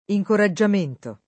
incoraggiamento [ i j kora JJ am % nto ]